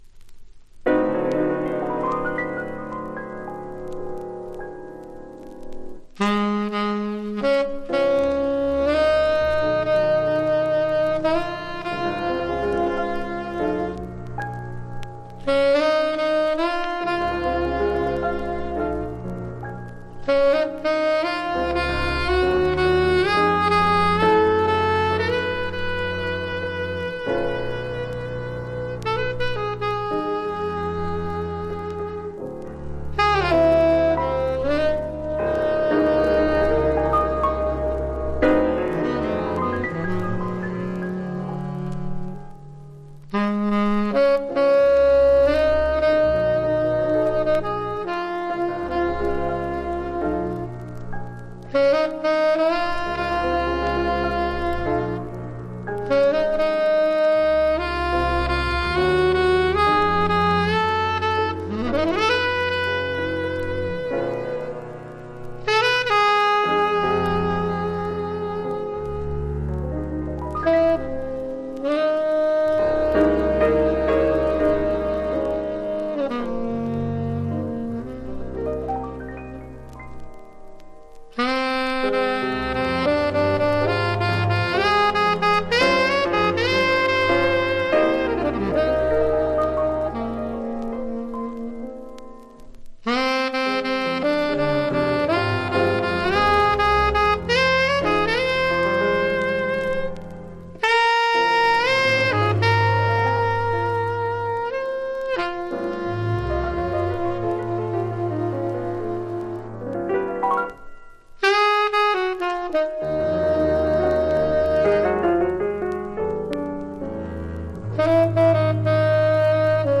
（プレス・小傷によりチリ、プチ音ある曲あり）※曲名をクリックす…